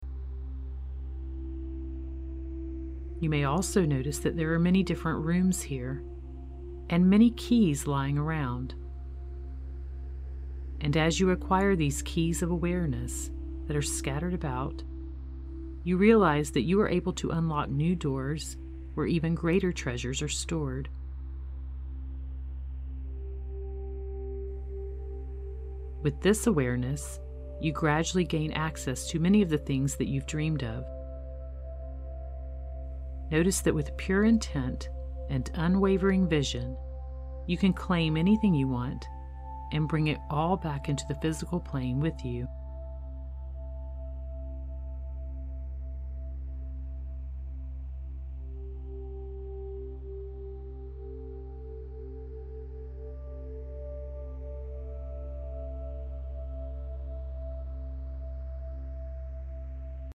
The instrumentation is entitled “Hypnotica” and contains swirling white noise, a hypnotic drone and a gentle wandering melody that was created with a bowed glass instrument. This Zen music is uncomplicated and very atmospheric. It offers a consistent melodic ambiance that encourages the mind to relax and become still.
GUIDED-MEDITATION-5TH-DIMENSION-SAMPLE.mp3